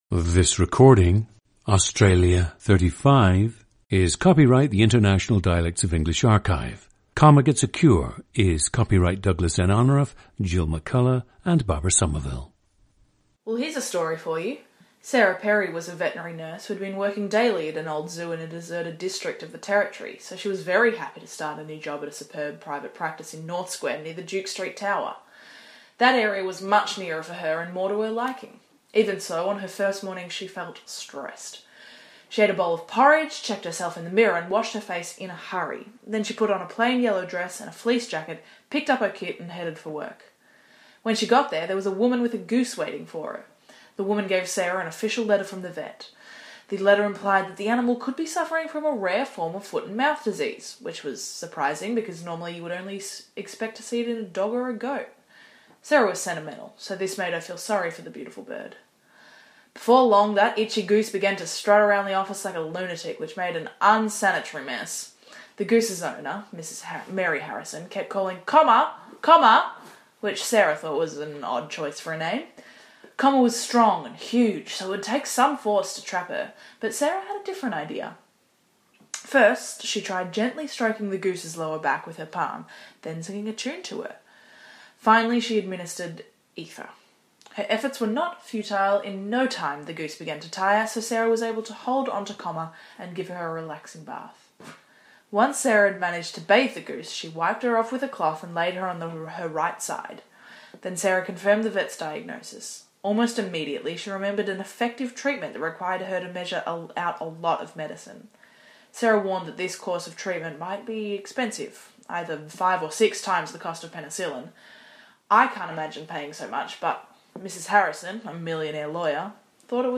GENDER: female
The subject has acting experience and says she has learned to do a “refined” Australian dialect at times. She also admits to modifying her dialect while living in Ireland so that her housemates could better understand her.
• Recordings of accent/dialect speakers from the region you select.
The recordings average four minutes in length and feature both the reading of one of two standard passages, and some unscripted speech.